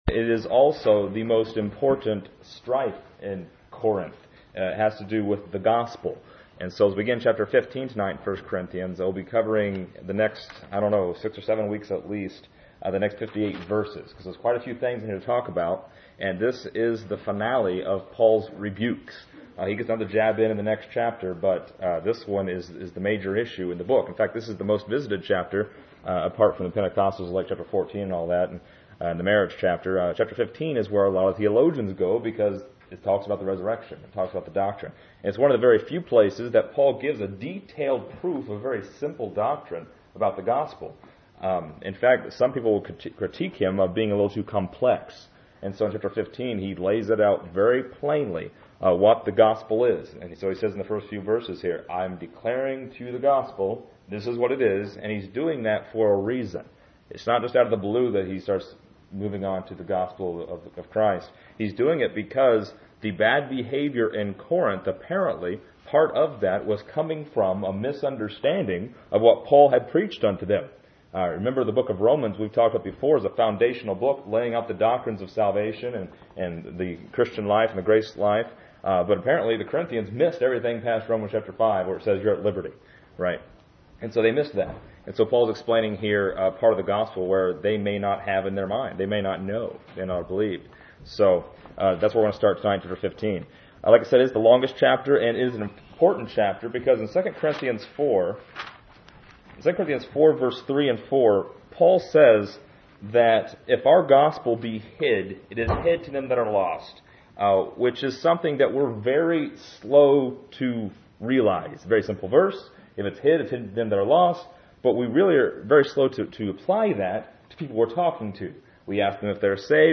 This lesson is part 39 in a verse by verse study through 1 Corinthians titled: The Gospel that Saves.